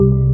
ORGAN-29.wav